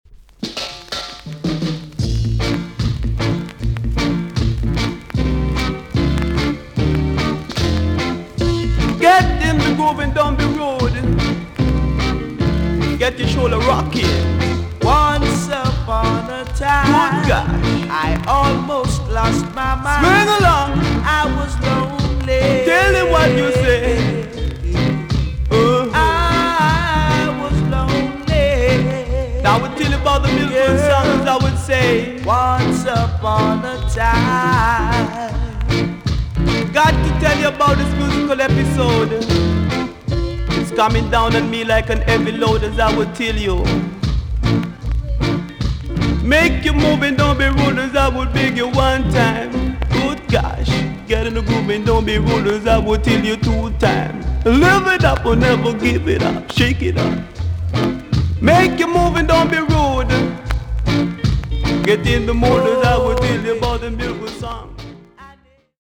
TOP >REGGAE & ROOTS
VG+~VG ok 軽いチリノイズが入ります。